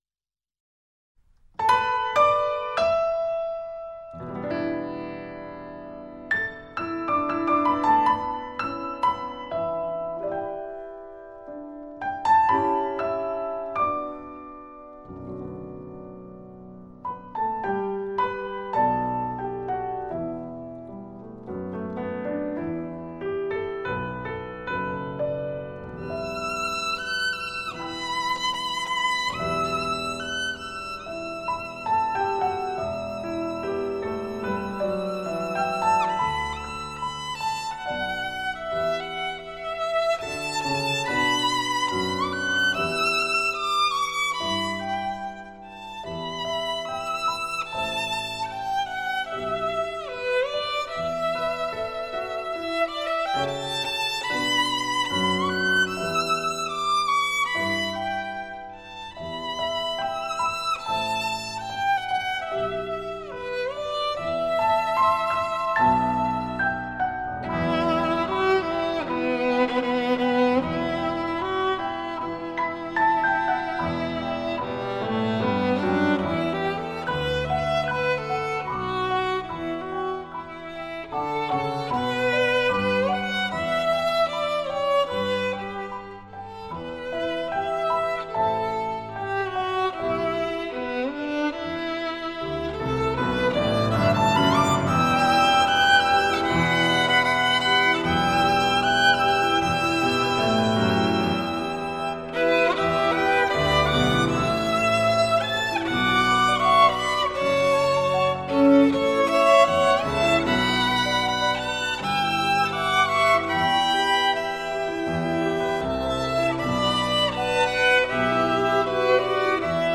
[分享]小提琴曲：《山丹丹开花红艳艳》